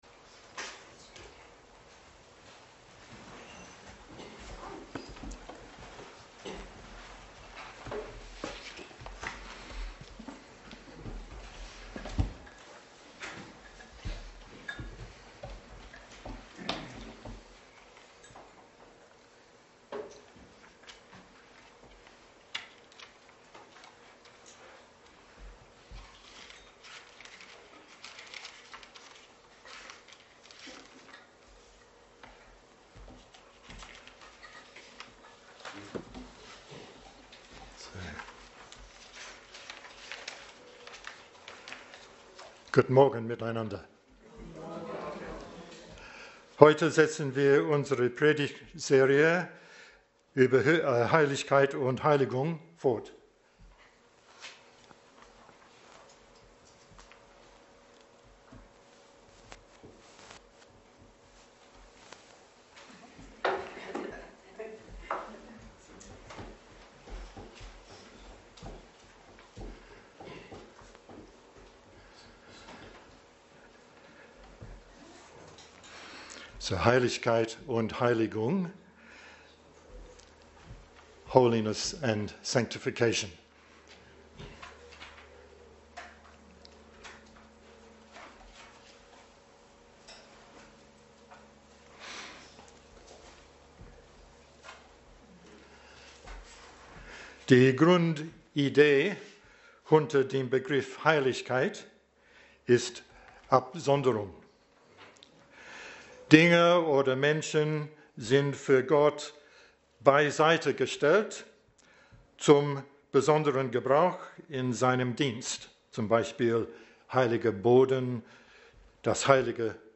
Dienstart: Predigt Themen: Gemeinde , Gnadengaben , Heiligung , Leib Christi